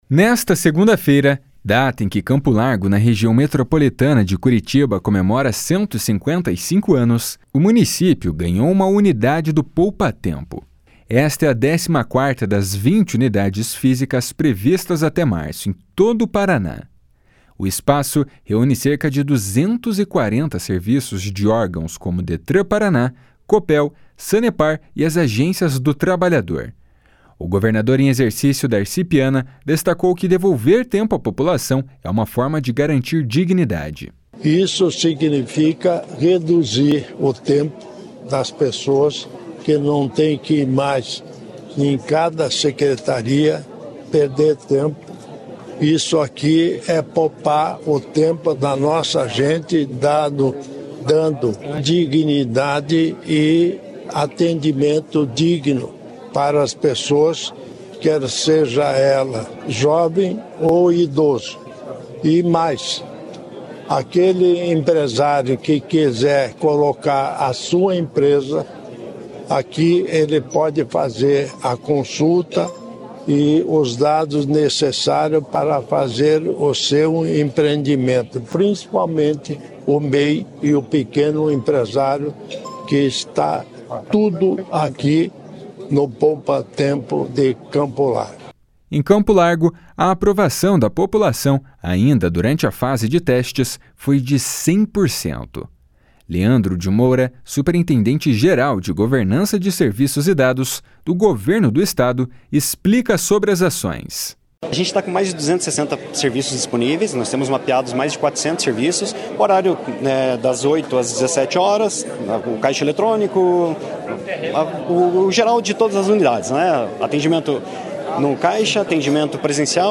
O espaço reúne cerca de 240 serviços de órgãos como Detran-PR, Copel, Sanepar e as Agências do Trabalhador. O governador em exercício Darci Piana destacou que devolver tempo à população é uma forma de garantir dignidade.
Para o prefeito Maurício Rivabem, a unidade representa mais praticidade.
O secretário estadual das Cidades, Guto Silva, destacou que o município cresceu nos últimos anos e hoje conta com mais de 130 mil habitantes.